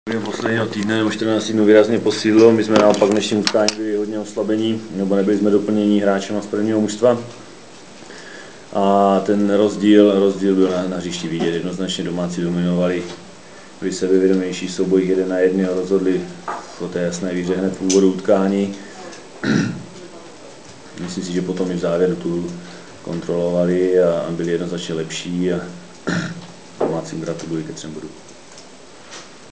Ohlasy trenérů v MP3: